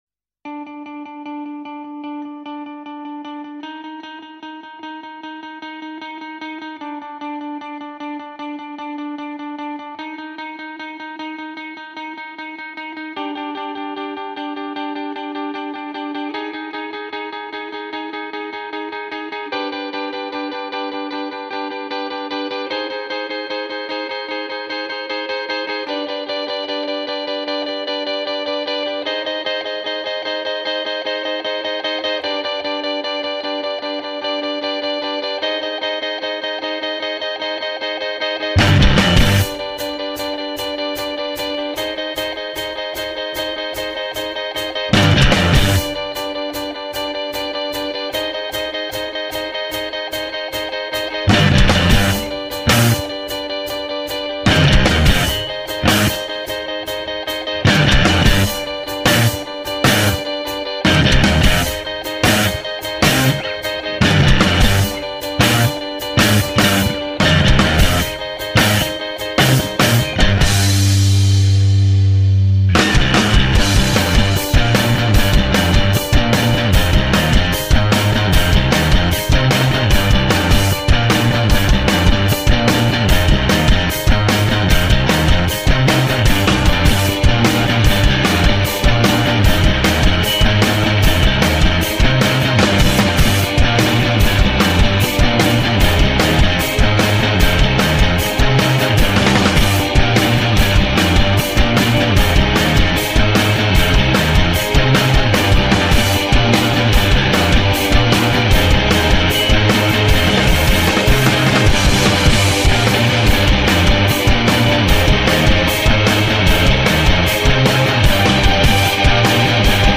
a two-piece band